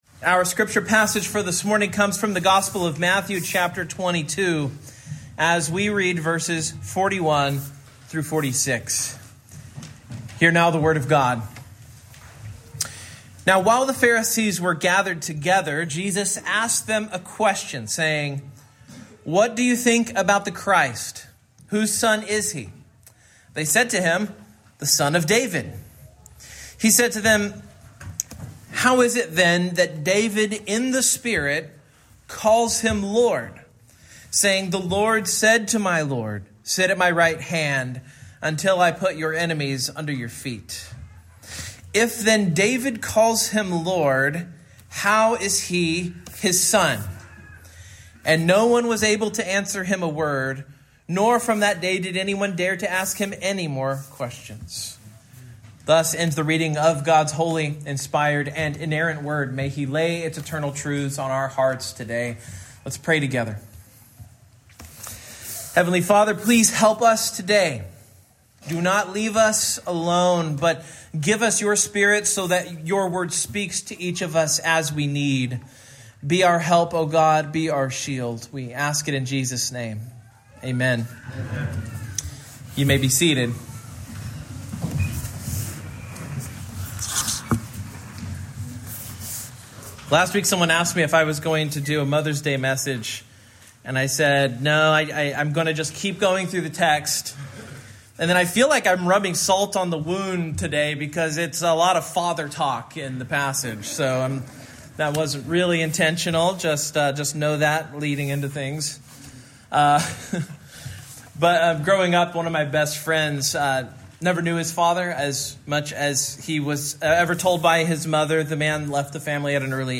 Matthew 22:41-46 Service Type: Morning Sermon Notes Main Point